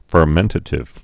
(fər-mĕntə-tĭv)